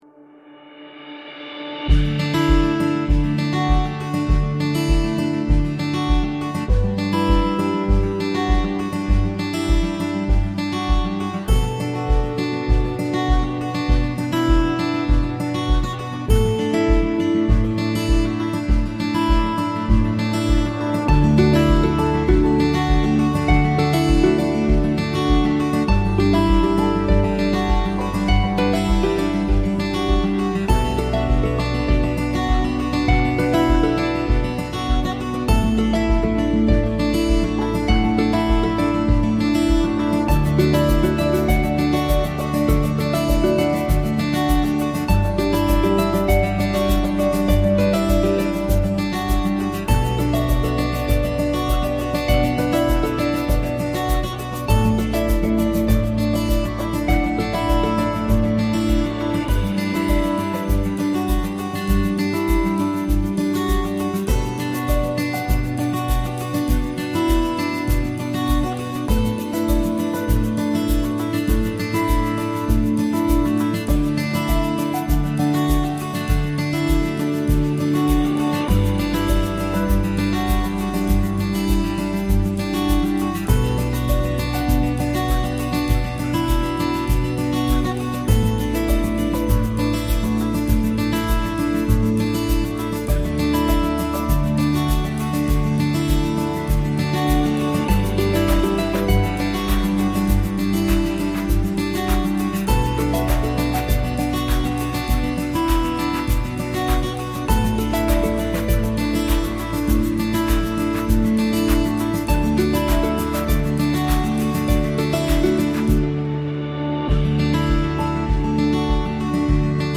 musica-suave-de-fondo-para-videos-y-presentacion.mp3